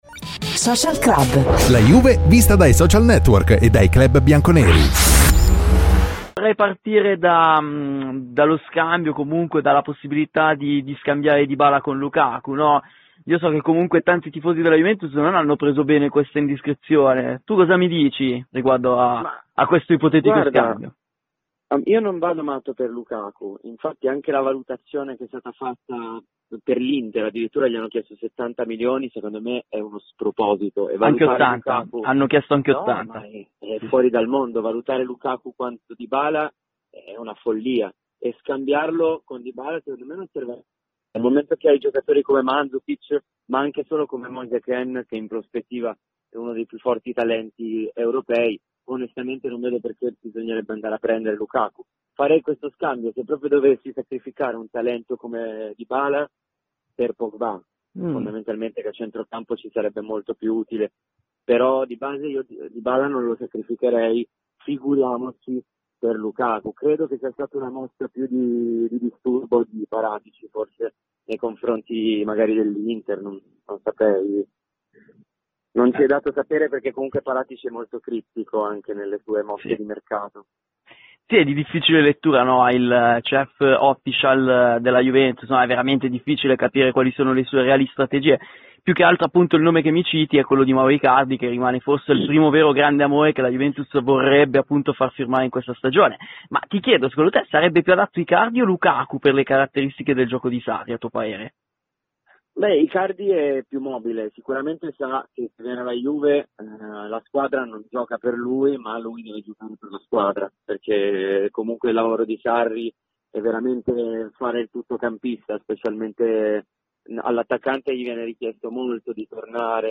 Ai microfoni di Radio Bianconera, nel corso di ‘Social Club’, ha parlato il rapper Shade, grande tifoso della Juventus: “Dybala-Lukaku? Io non vado matto per il belga, secondo me le cifre chieste sono assurde.